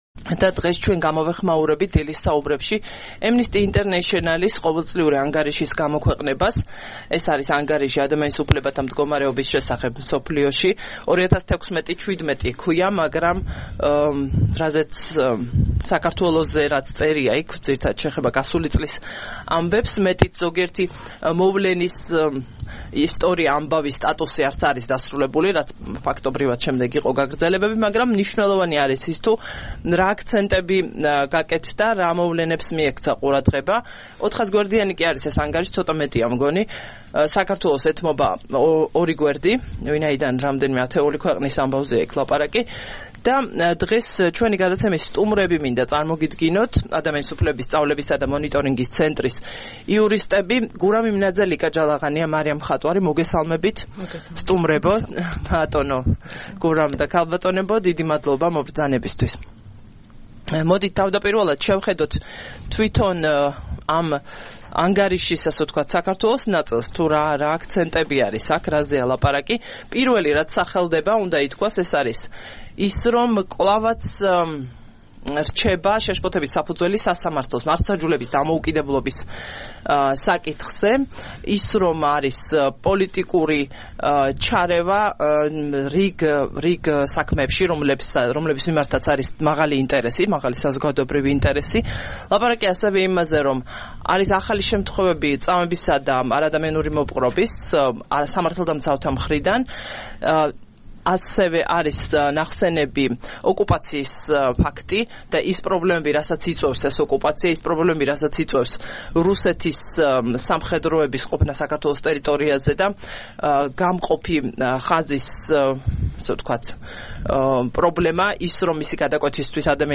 EMC-ს იურისტები.